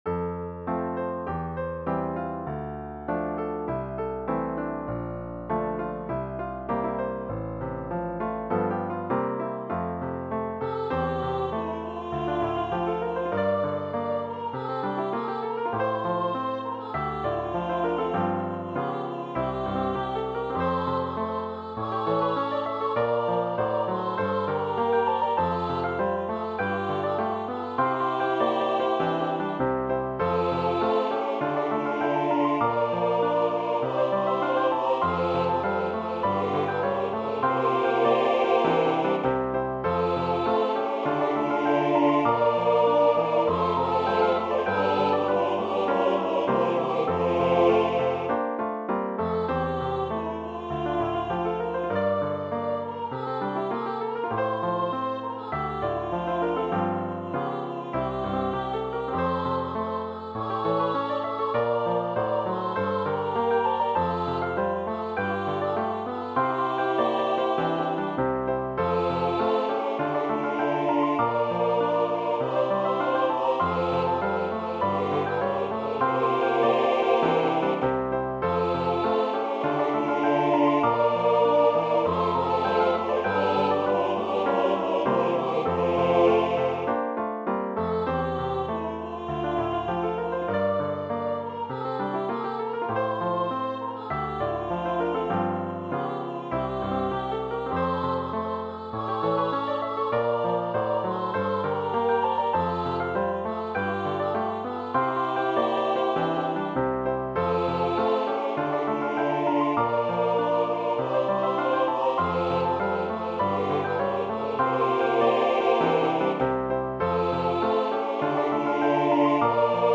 Для Смешанного хора или Квартета/Группы